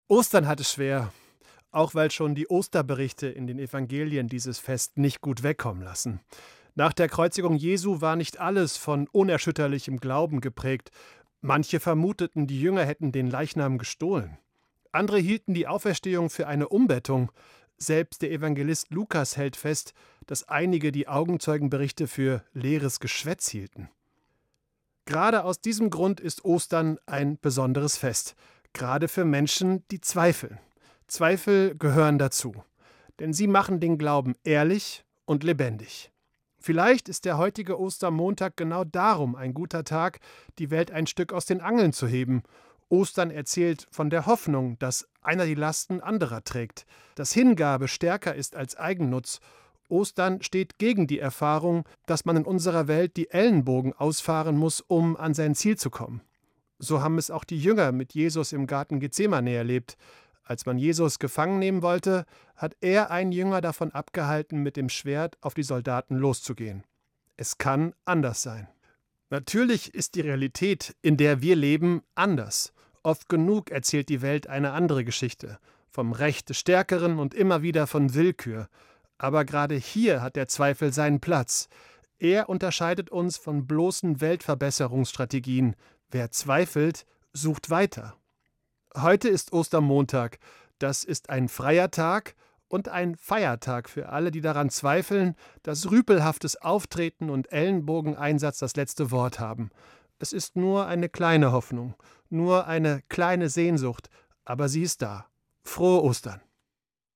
Evangelischer Pfarrer, Bad Hersfeld